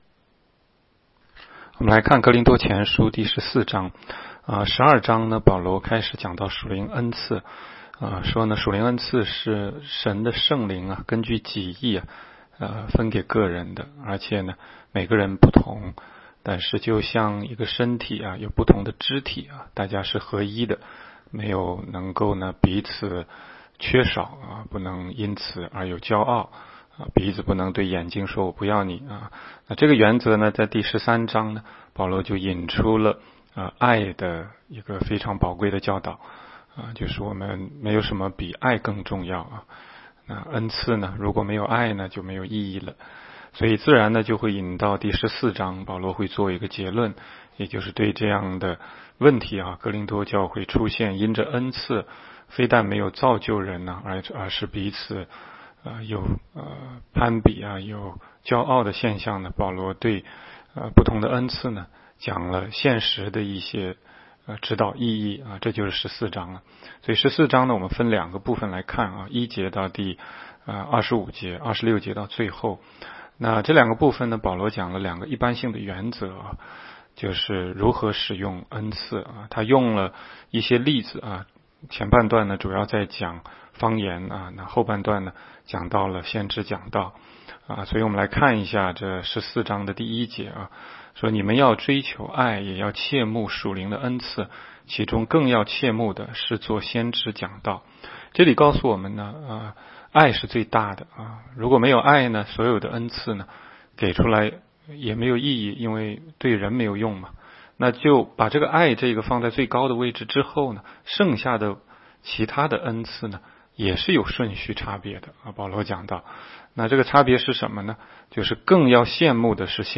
16街讲道录音 - 每日读经-《哥林多前书》14章
每日读经